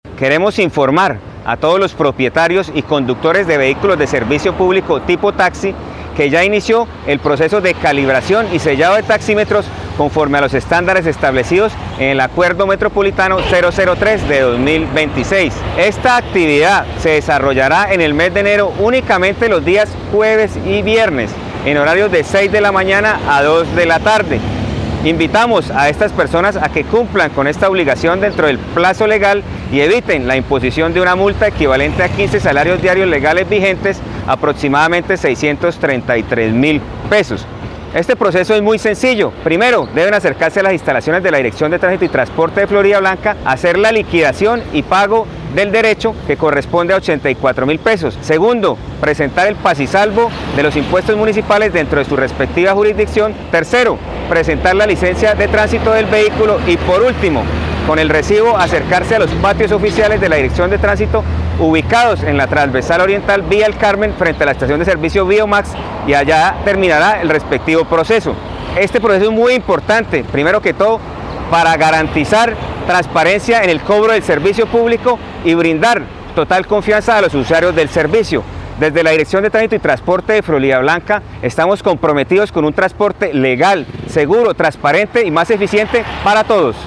Jahir Castellanos, Director Director de Tránsito de Floridablanca